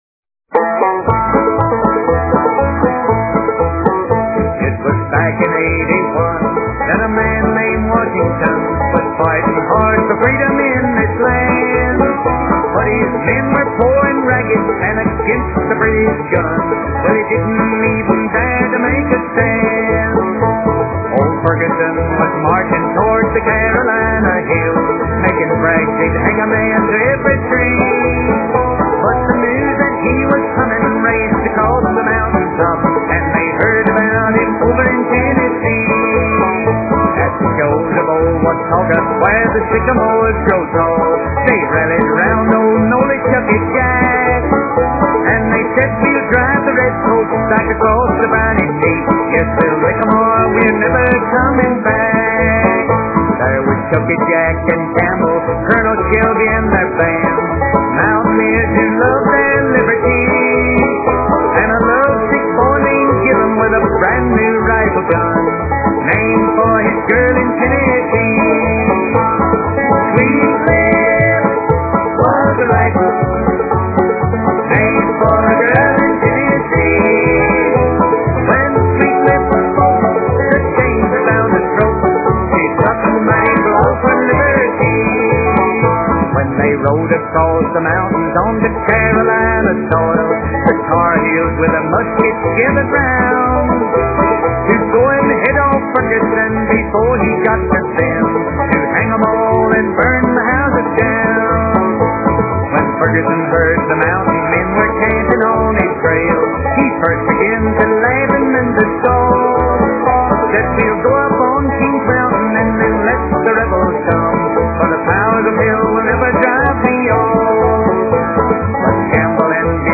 Sweet Lips is said to have been the name of the rifle that killed Ferguson. So listen to “Sweet Lips”, a real country tune!